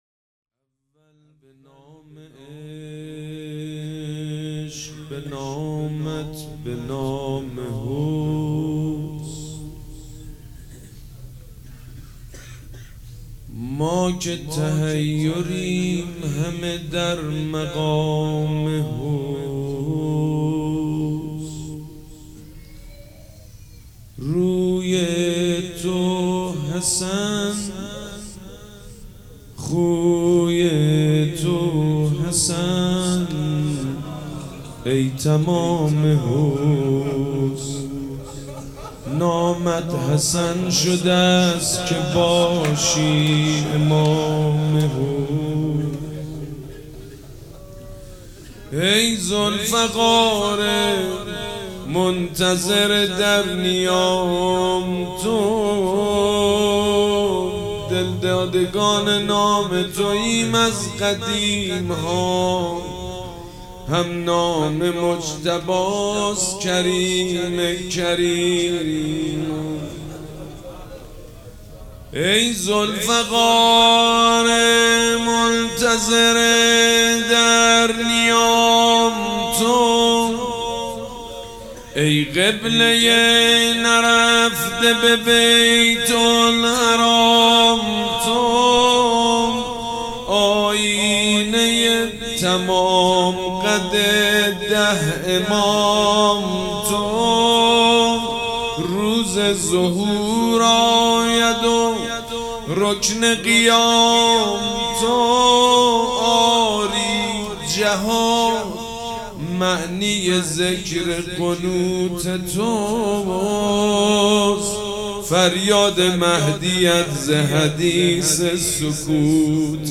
شب شهادت امام حسن عسکری ۹۸
روضه بخش دوم favorite
سبک اثــر روضه
روضه دوم.mp3